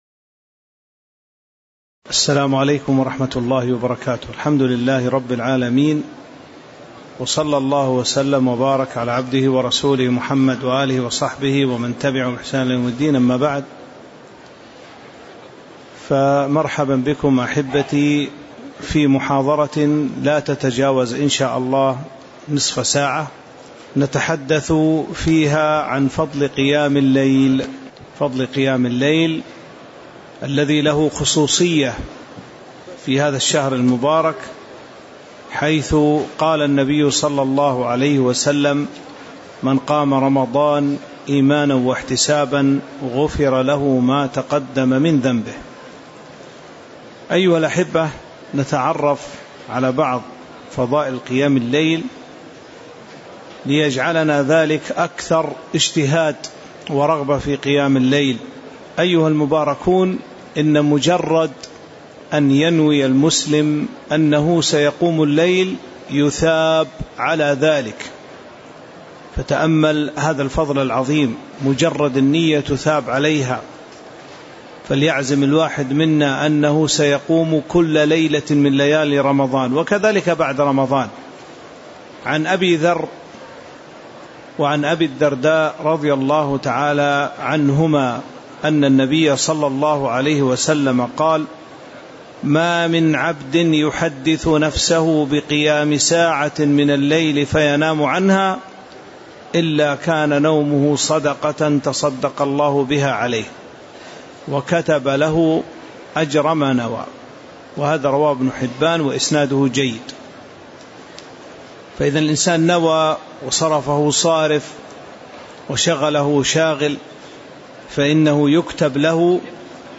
تاريخ النشر ٣ رمضان ١٤٤٤ هـ المكان: المسجد النبوي الشيخ